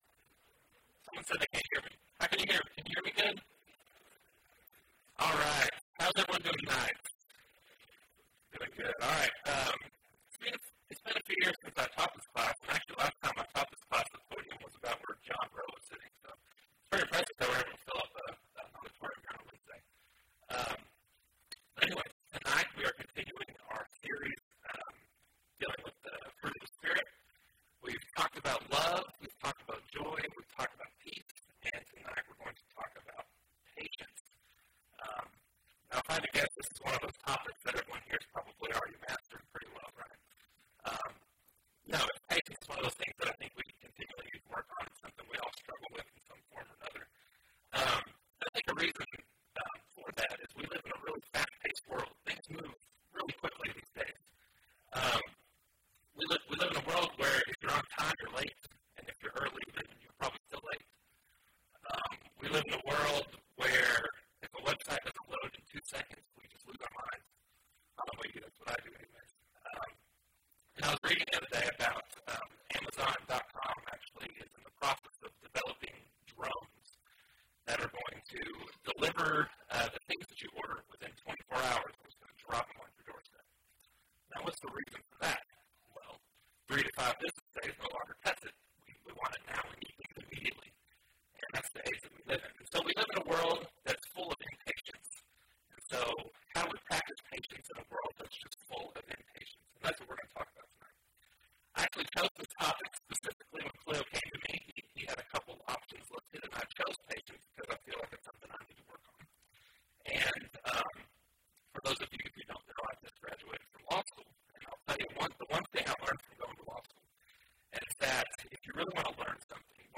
Wednesday PM Bible Class